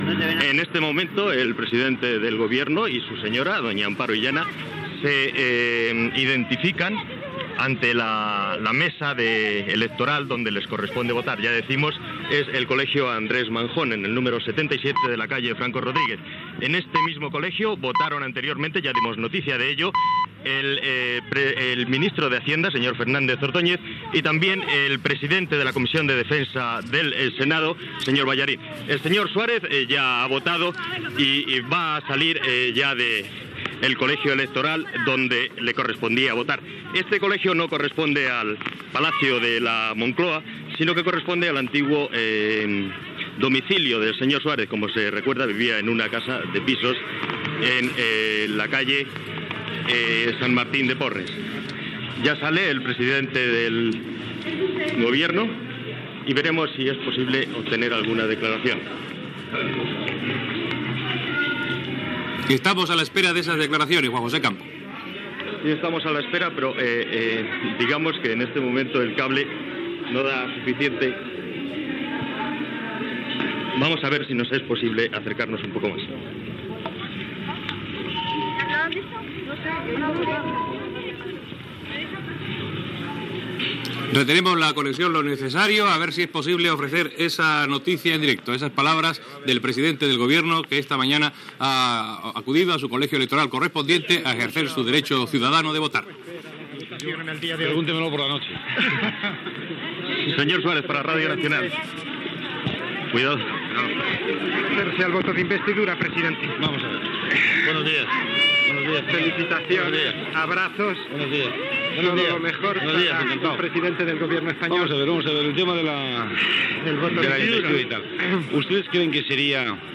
Cap a les 10 del matí el president del govern espanyol Adolfo Suárez vota a en el seu col·legi electoral a Madrid el dia del referèndum per aprovar la Constitució espanyola . Declaracions d'Adolfo Suárez
Informatiu